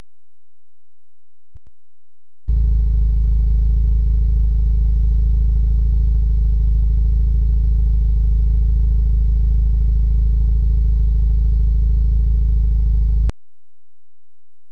マフラー音
・低音のドロドロ音で、いいね。
・音：アイドル⇒ぽぼぼ・・・・・・・・低音。
吹かす⇒ボオン・・・・・ン。